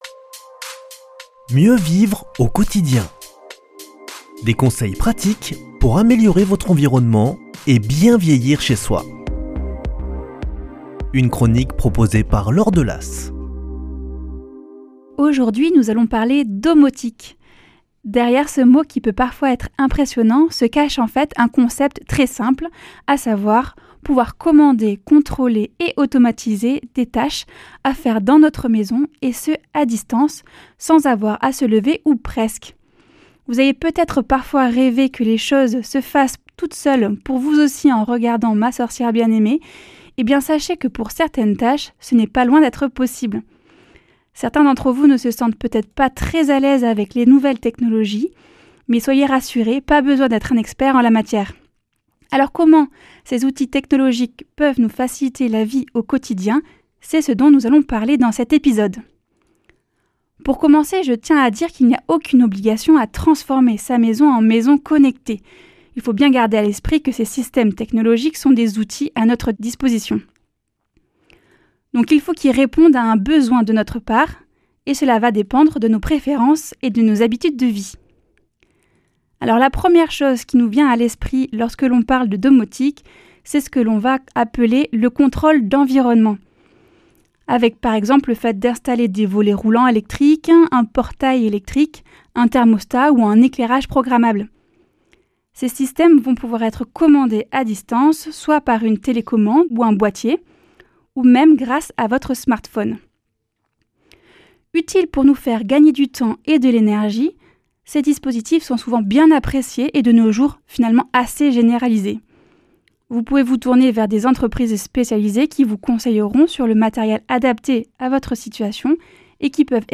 Ergothérapeute